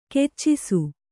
♪ keccisu